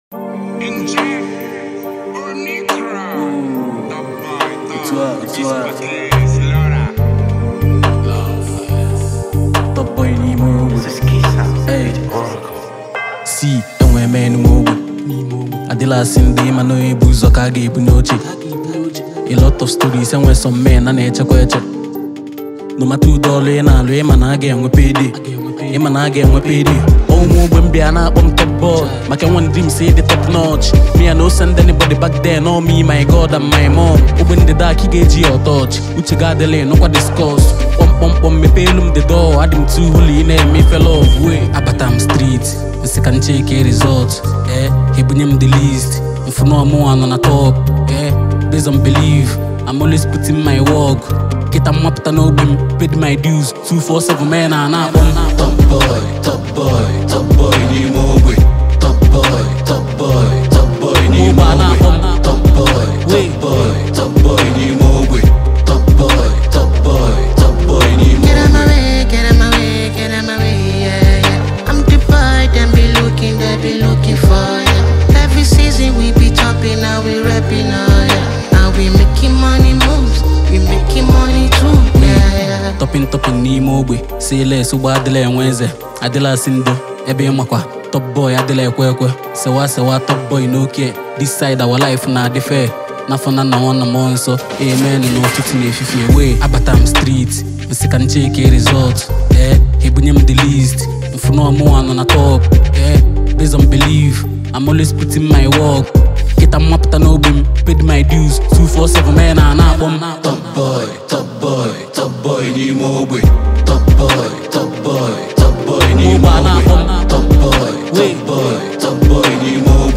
Nigerian / African Music
Genre: Afrobeats